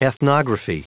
Prononciation du mot ethnography en anglais (fichier audio)
Prononciation du mot : ethnography